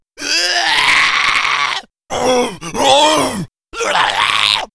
Monster2.wav